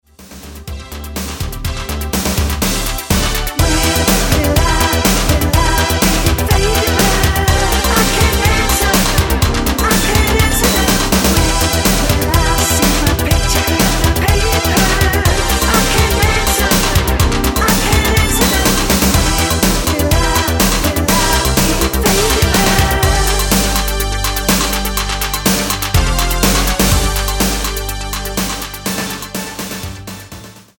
Tonart:Em-Fm mit Chor
Die besten Playbacks Instrumentals und Karaoke Versionen .